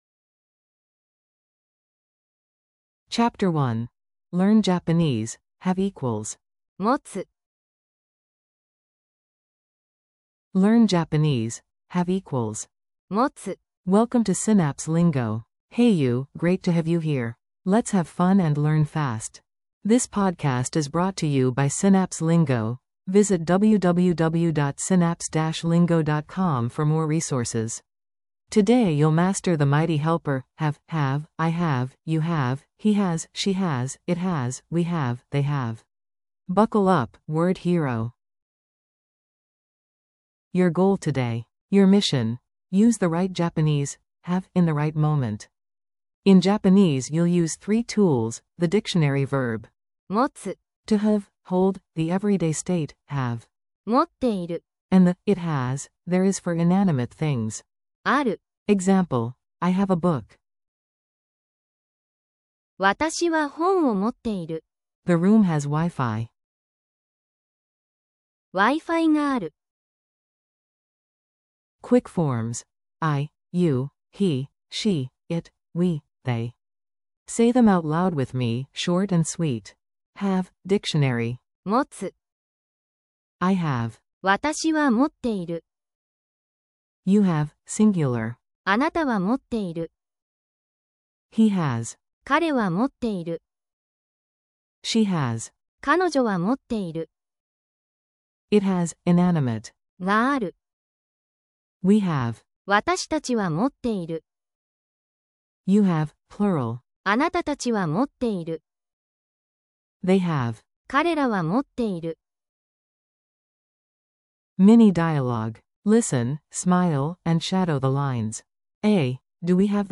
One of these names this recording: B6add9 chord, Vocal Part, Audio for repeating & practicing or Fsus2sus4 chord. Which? Audio for repeating & practicing